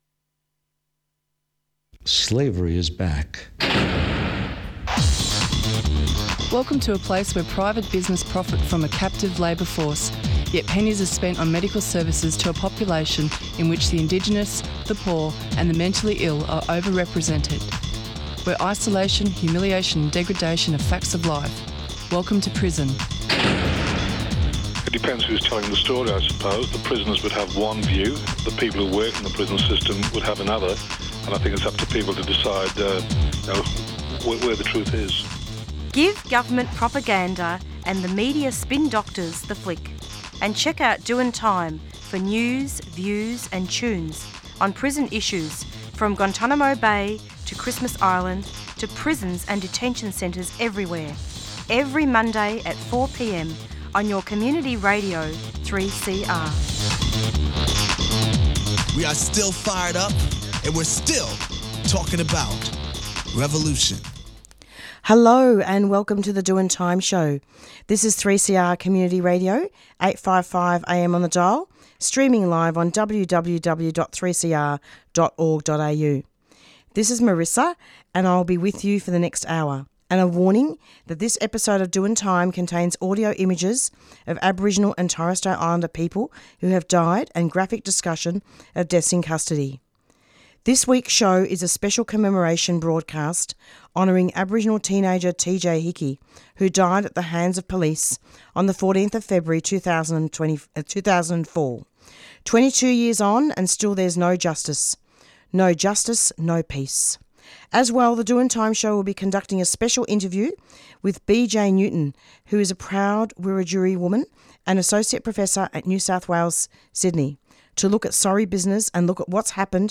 This week's show is a Special Commemoration Broadast